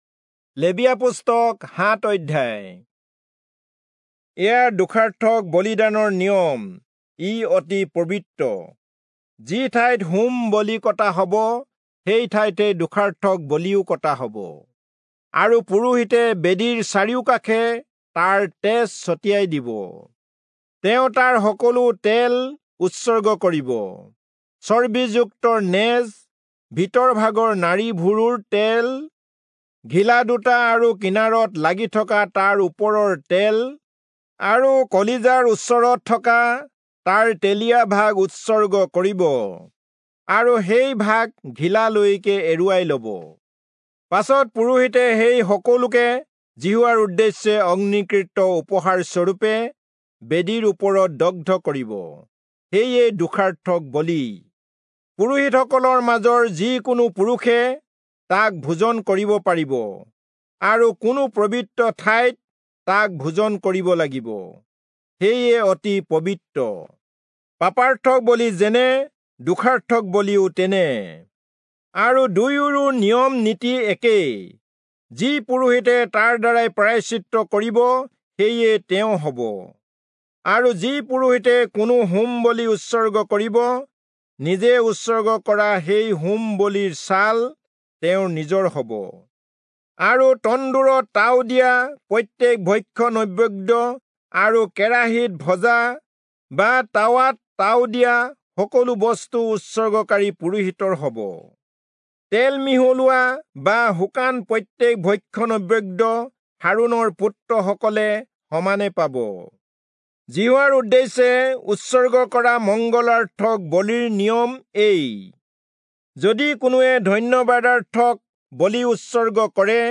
Assamese Audio Bible - Leviticus 1 in Bnv bible version